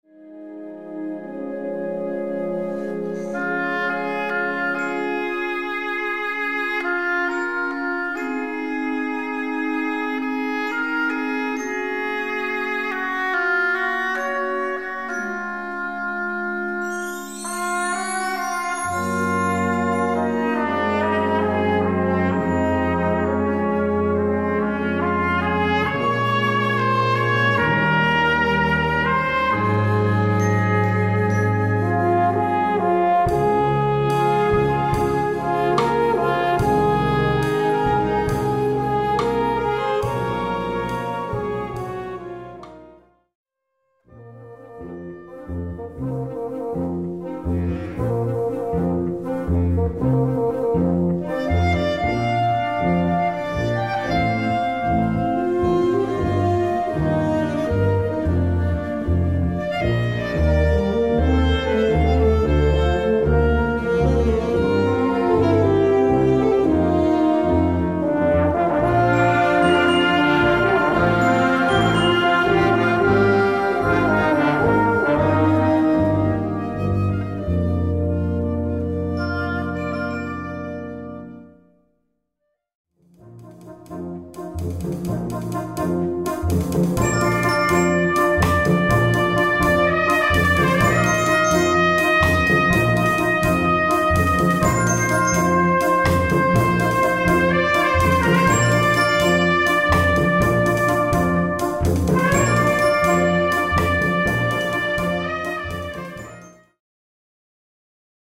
Gattung: Filmmusik
A4 Besetzung: Blasorchester Zu hören auf